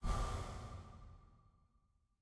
sfx-dh-ui-generic-hover.ogg